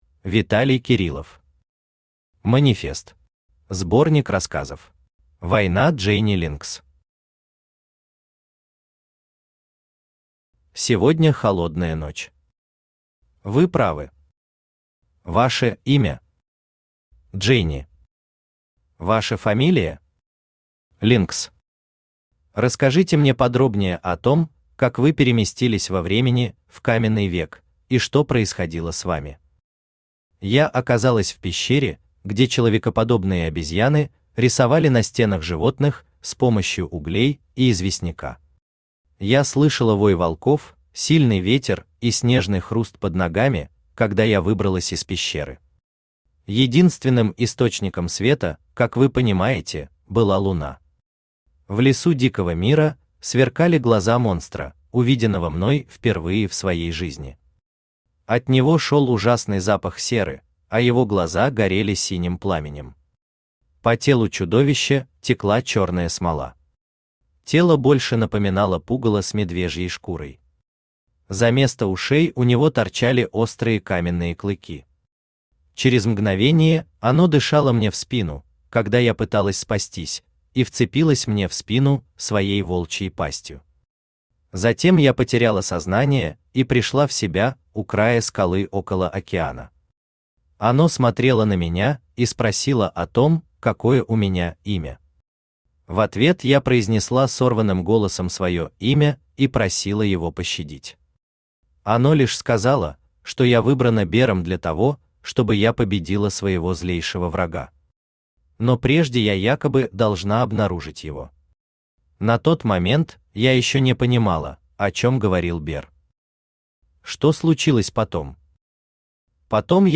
Аудиокнига Манифест. Сборник рассказов | Библиотека аудиокниг
Сборник рассказов Автор Виталий Александрович Кириллов Читает аудиокнигу Авточтец ЛитРес.